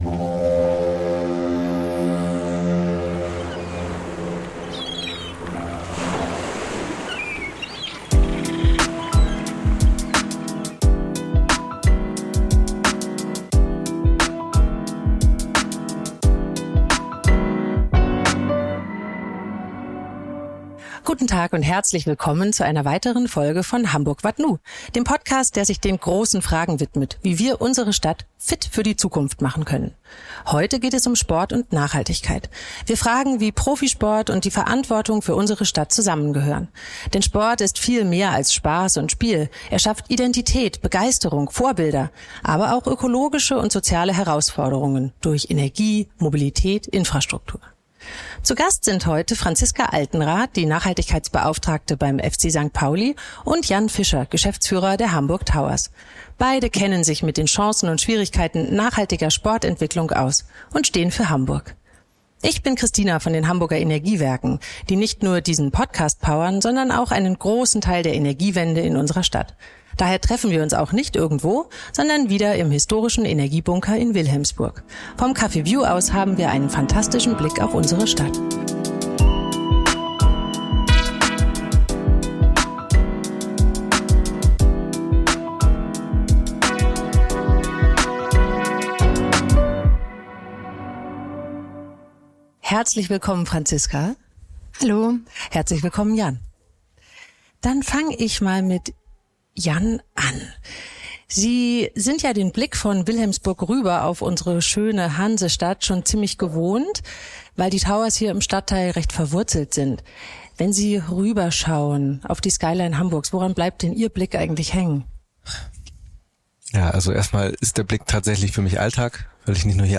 Es geht um Verantwortung auf und neben dem Spielfeld, Fan-Kultur, Stadtteilverbundenheit und die Frage, wie Sport Hamburgs Zukunft mitgestalten kann – auch mit Blick darauf, wie Nachhaltigkeit in einer Großstadt ganz praktisch umgesetzt wird. Aufgenommen im Café Vju im Energiebunker Wilhelmsburg – dort, wo Sport, Stadtgesellschaft und Energiewende zusammenkommen und auch die Hamburger Energiewerke an Lösungen für eine klimafreundliche Energieversorgung arbeiten.